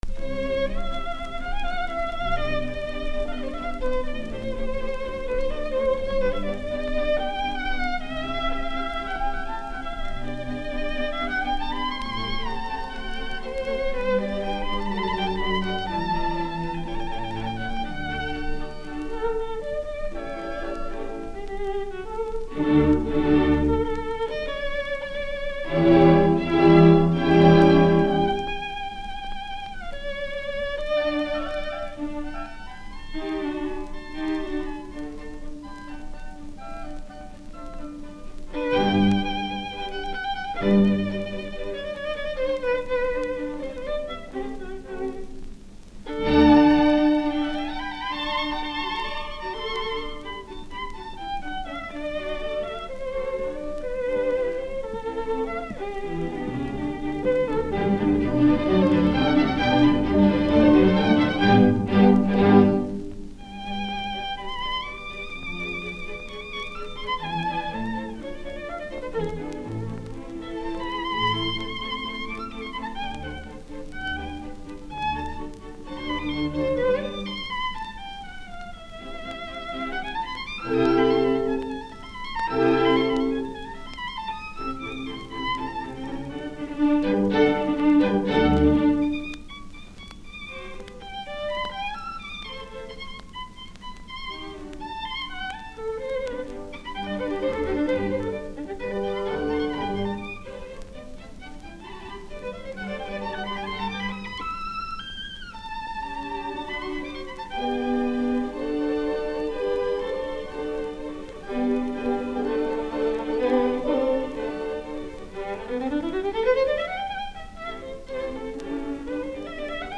Vintage DIY audio articles & audiofile shellac and vinyl remastering
Yehudi-Menuhin-Mozart-violin-concerto-No.3-1b-Allegro.mp3